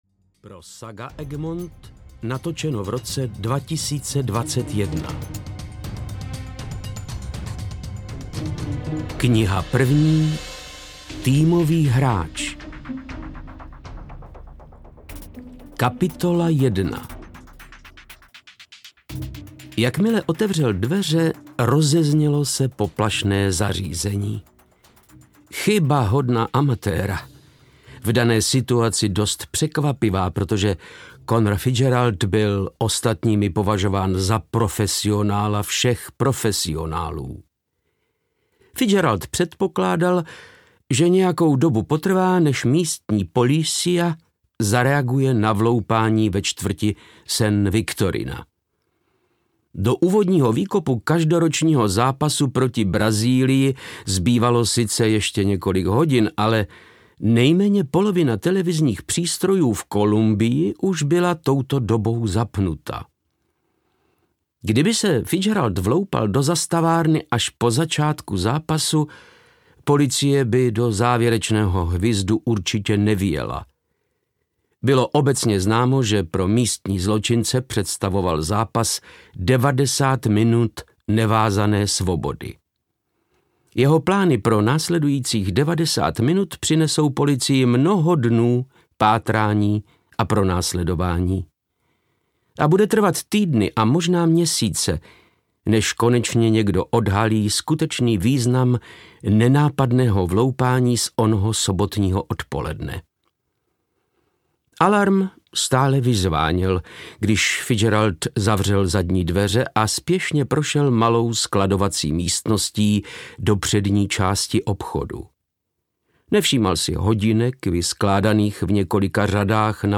Jedenácté přikázání audiokniha
Ukázka z knihy
• InterpretVáclav Knop